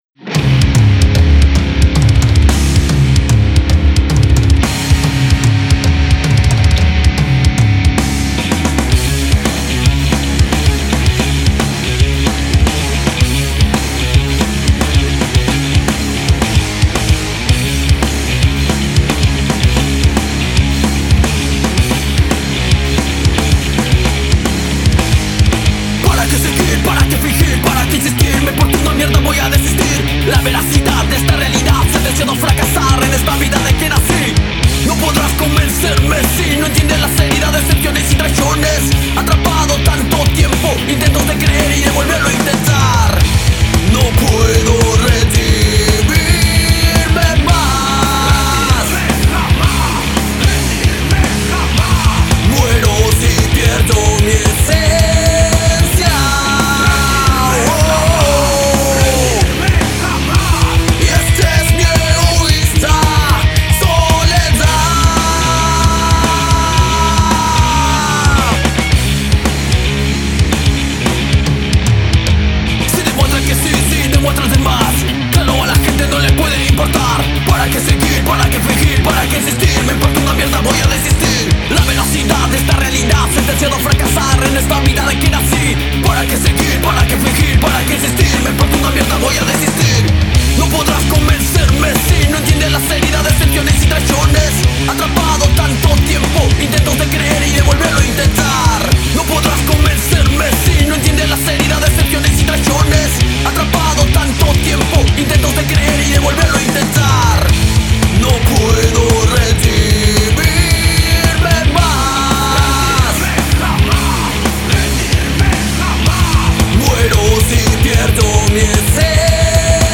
Banda Nu Metal / Metal Core